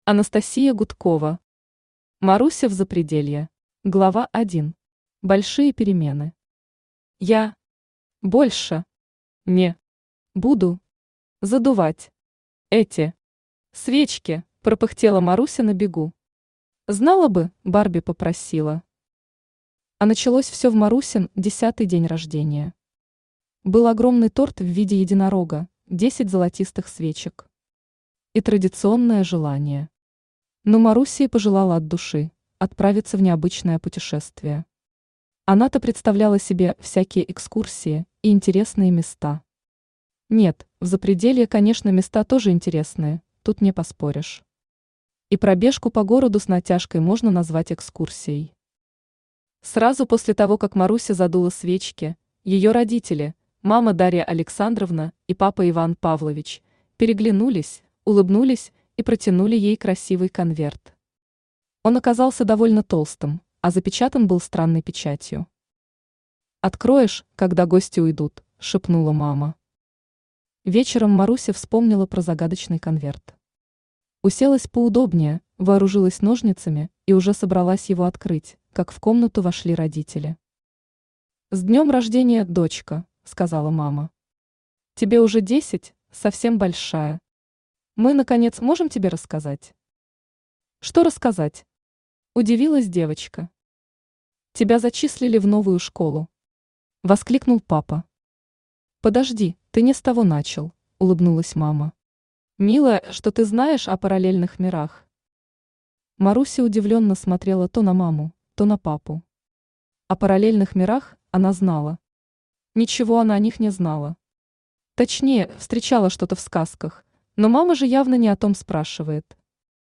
Аудиокнига Маруся в Запределье | Библиотека аудиокниг
Aудиокнига Маруся в Запределье Автор Анастасия Александровна Гудкова Читает аудиокнигу Авточтец ЛитРес.